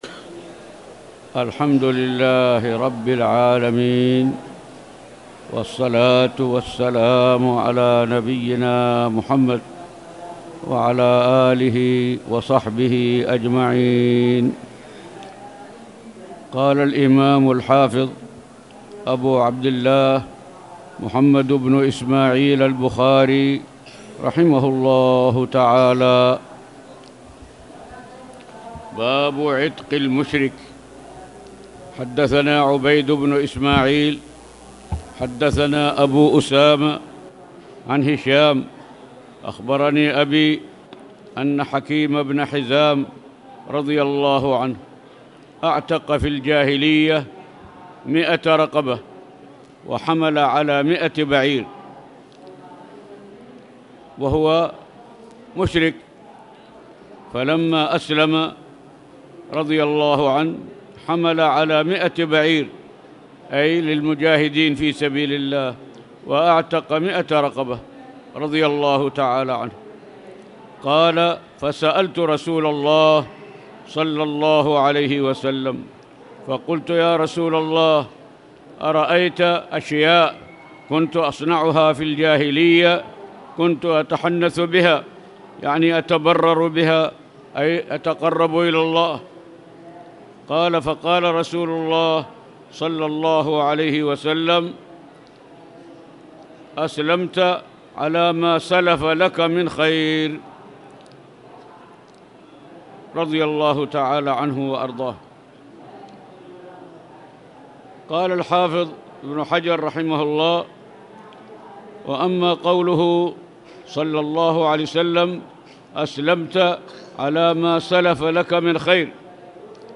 تاريخ النشر ٢٢ رجب ١٤٣٨ هـ المكان: المسجد الحرام الشيخ